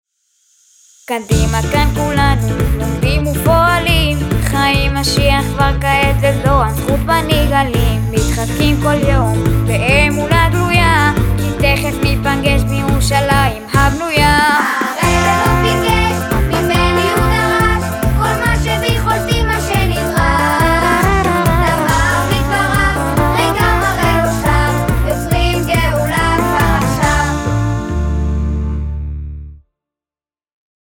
ג'ינגל ווקאלי